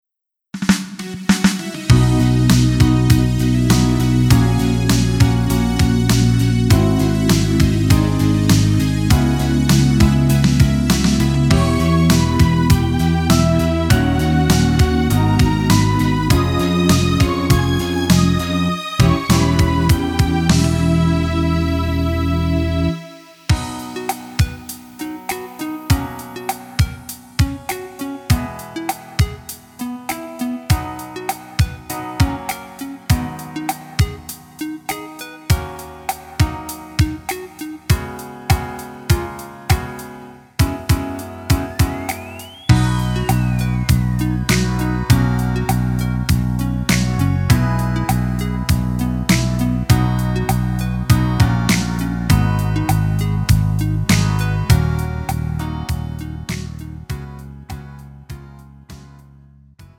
음정 원키
장르 구분 Pro MR